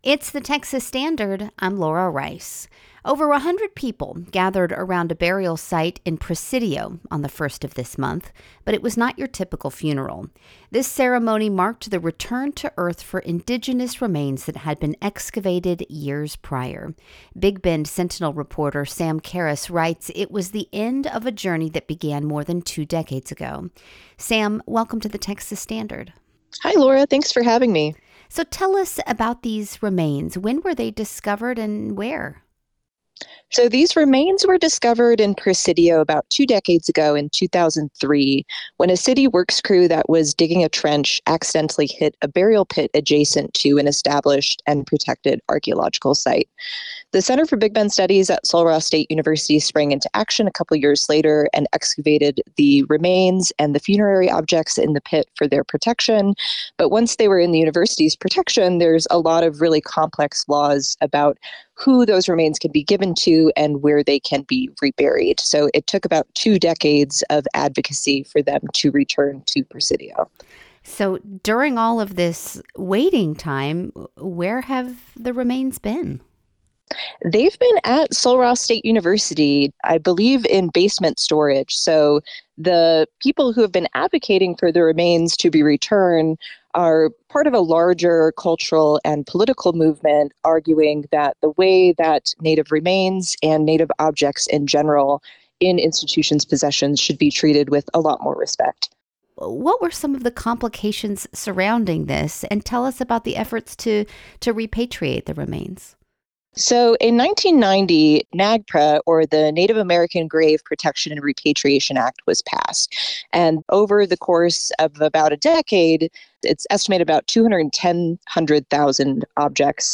The interview lasts 5 minutes and 26 seconds.